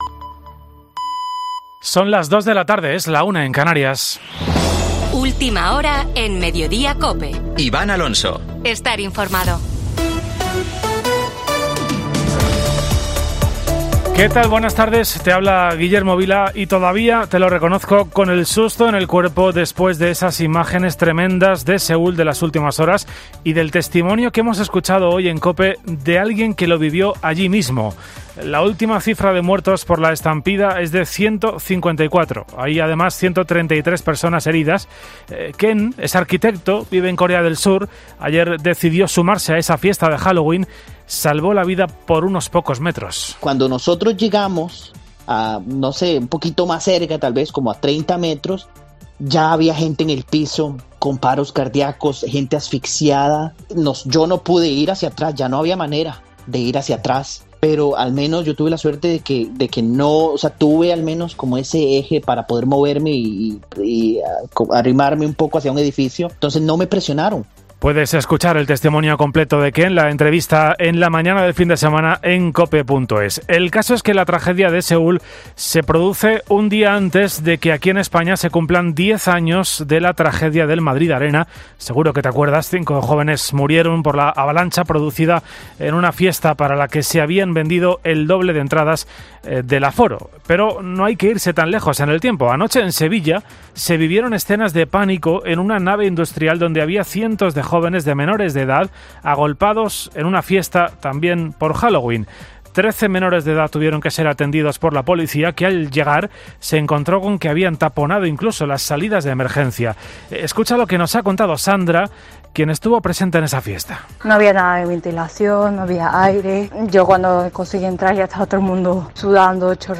Boletín de noticias de COPE del 30 de octubre de 2022 a las 14.00 horas